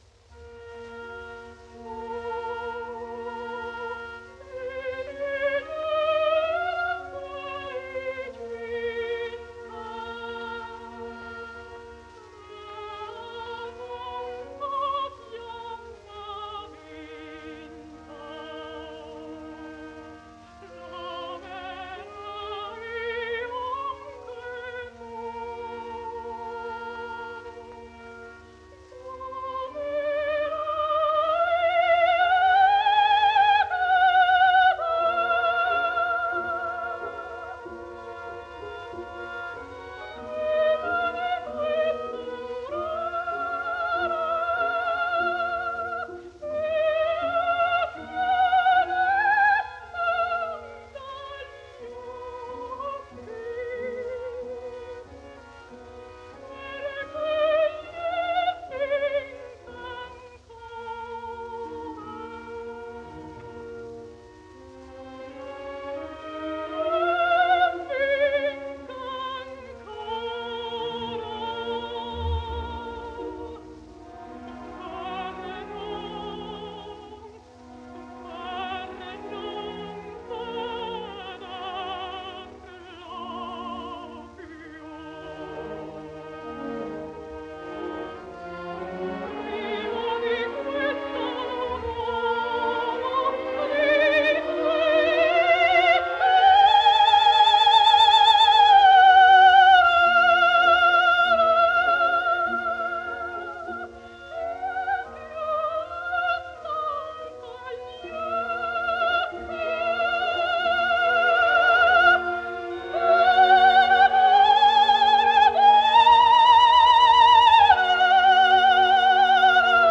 Maria Maddalena Olivero); 25 марта 1910, Салуццо — 8 сентября 2014, Милан) — итальянская оперная певица, сопрано.